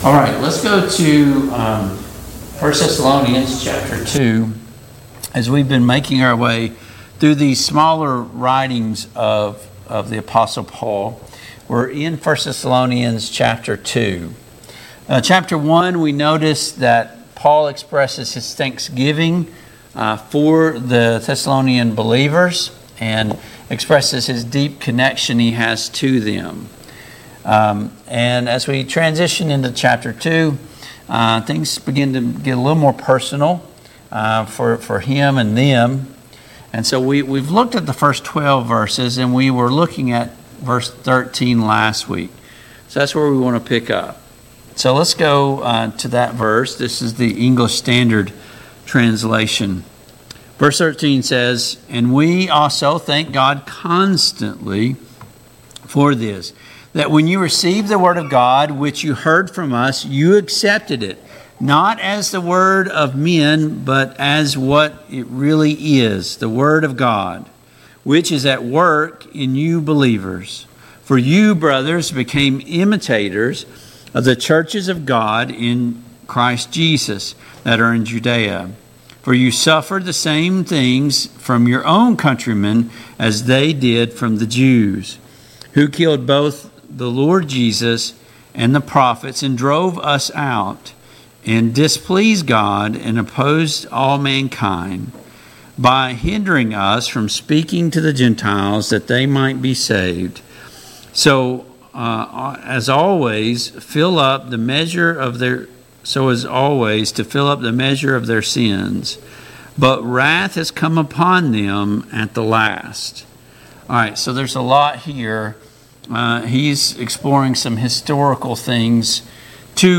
Passage: 1 Thessalonians 2:13-20 Service Type: Mid-Week Bible Study